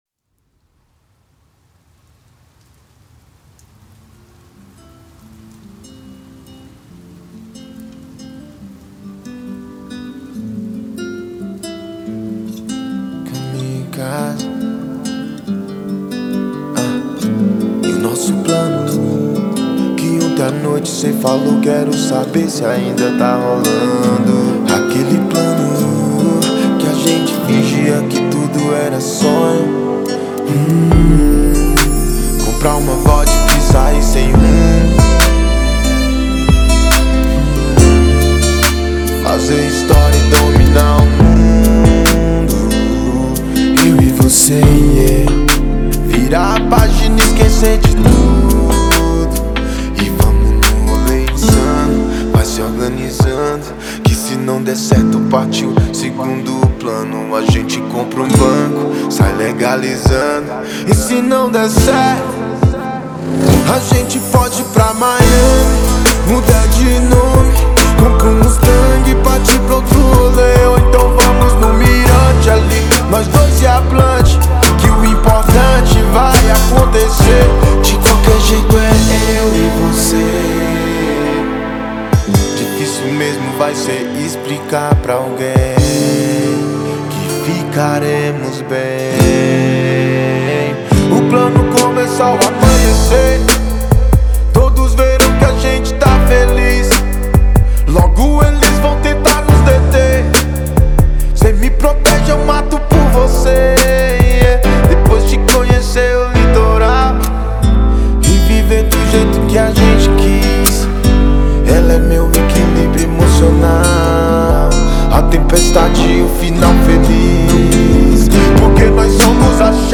2025-02-24 22:13:08 Gênero: Hip Hop Views